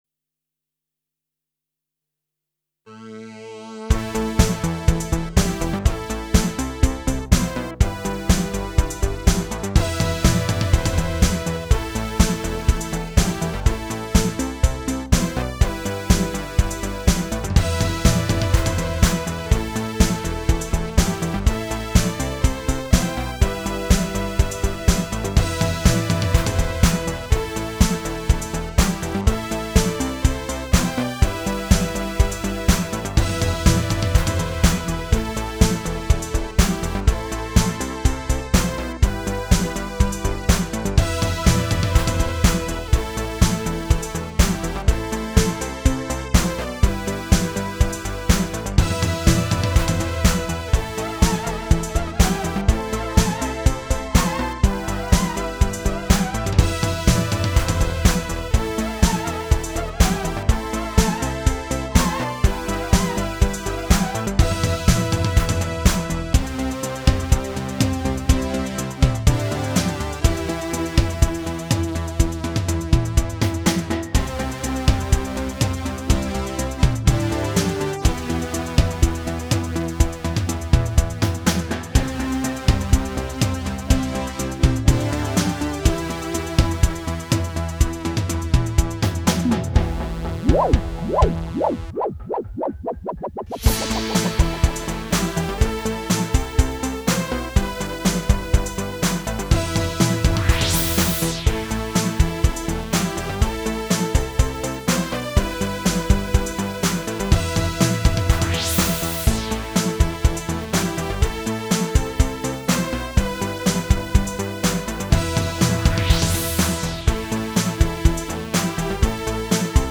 Last week he asked me to collect a microfilm of their work so far, naturally I've made a copy of it and here's one of the unfinished tracks...
Those boys won't be happy when they find out what i've done, i'm putting myself at risk here, I especially fear that big one who does the camp rapping.